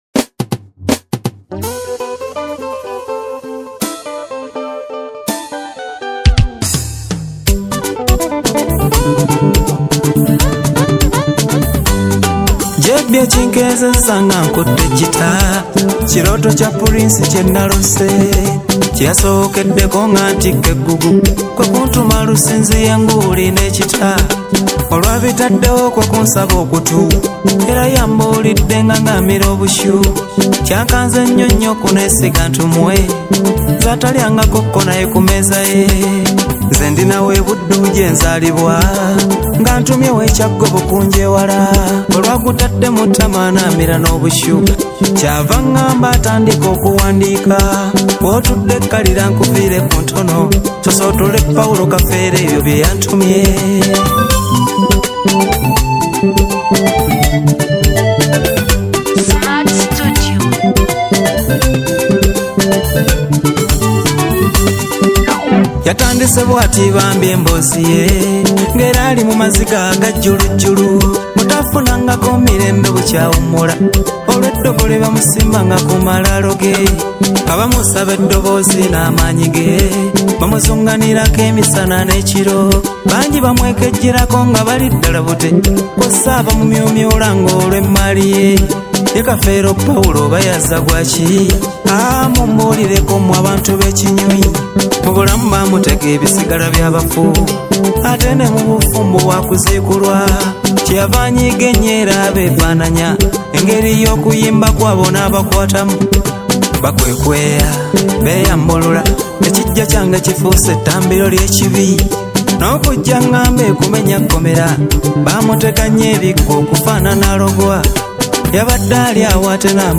Kadongo Kamu